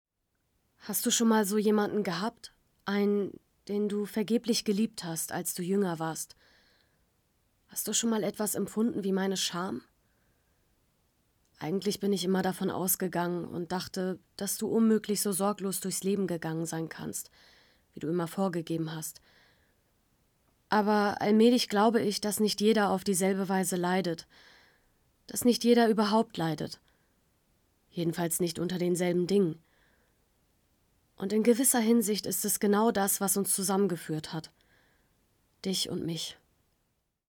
dunkel, sonor, souverän, hell, fein, zart, markant, sehr variabel
Mittel minus (25-45)
Norddeutsch